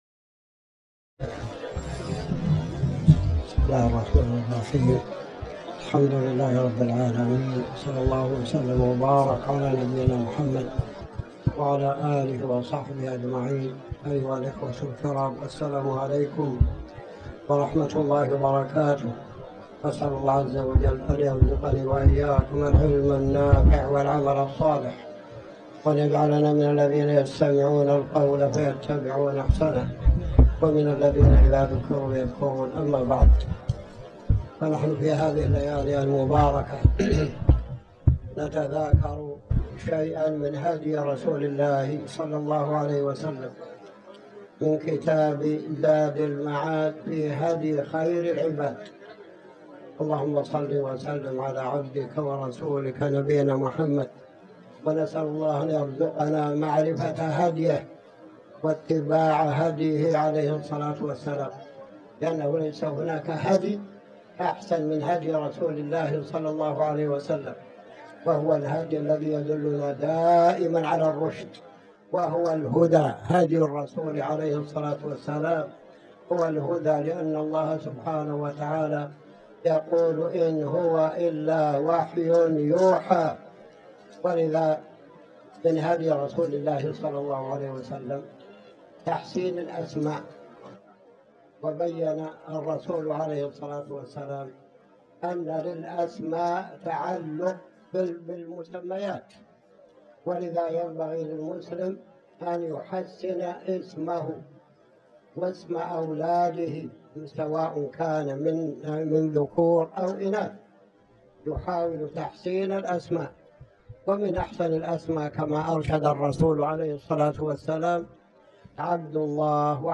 تاريخ النشر ٢٣ جمادى الأولى ١٤٤٠ هـ المكان: المسجد الحرام الشيخ